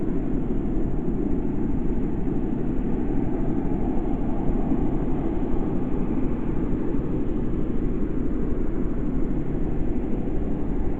2020 WILTON ENVIRONMENTAL NOISE